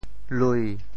潮州府城POJ lūi 国际音标 [lui]